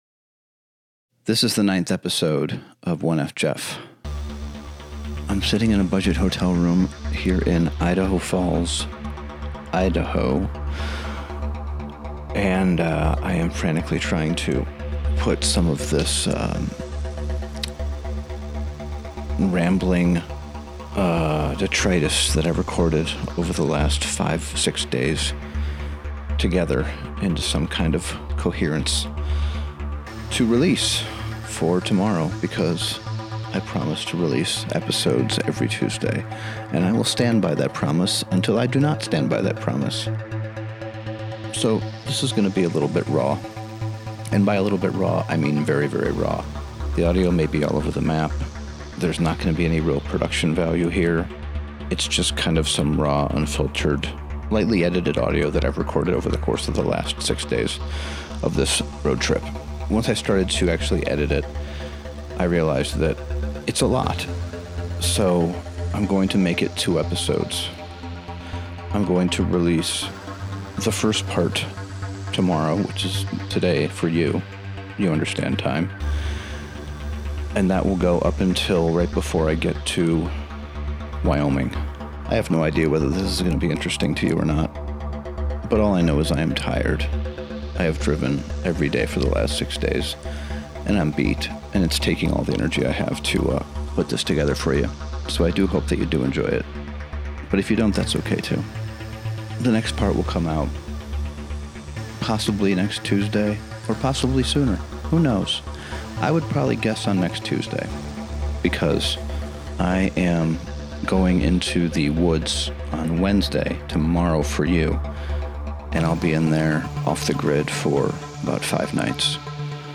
Conversations with interesting humans, plus occasional unplanned solo rambles, surprise guests, and audio detritus from my archive.